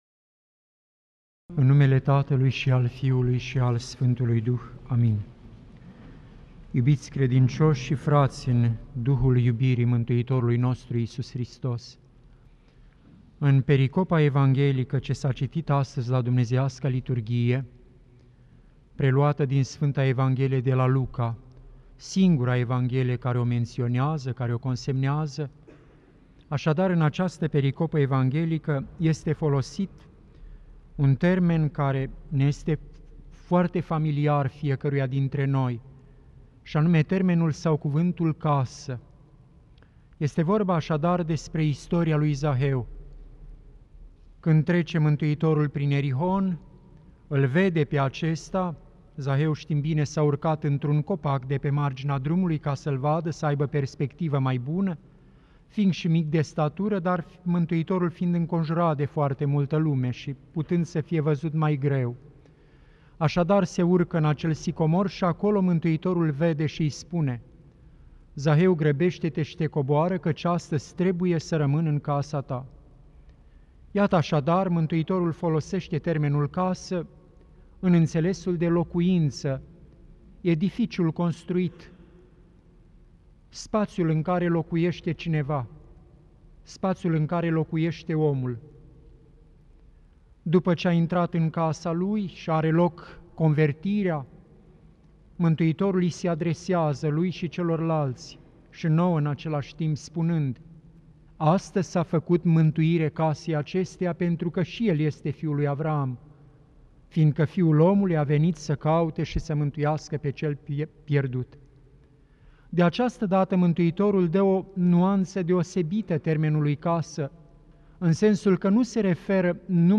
Cuvinte de învățătură Predică la Duminica a 32-a după Rusalii